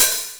TR 909 Open Hat 02.wav